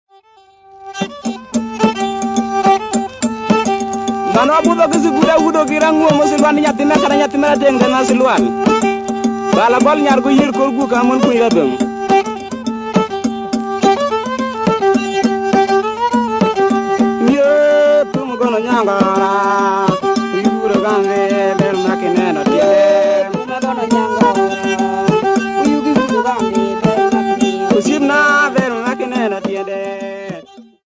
Folk music--Africa
Folk music--Kenya
Field recordings
sound recording-musical
7" Reel